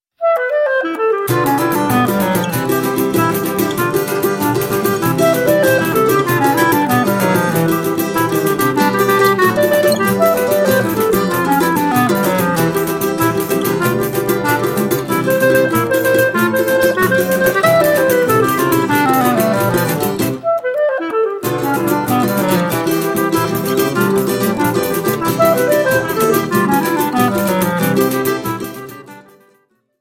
clarinete